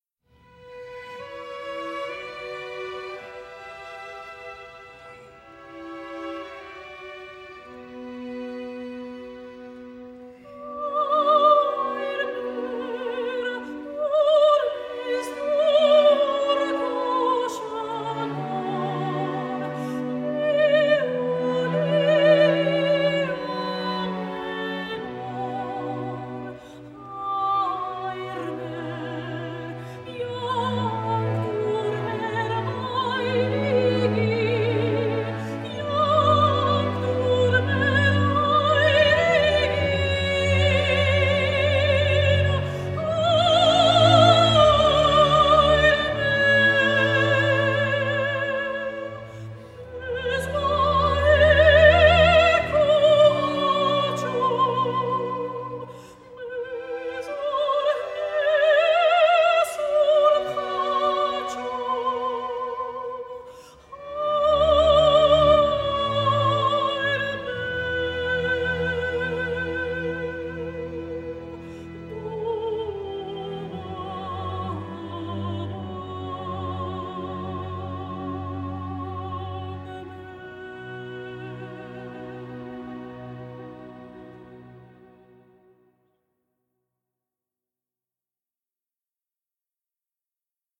* ISABEL BAYRAKDARIAN (soprano) - MANOOGNEROO HAYR MER (`Отче наш` - для маленьких детей)